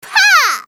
archer_f_voc_skill_spinningfire_d.mp3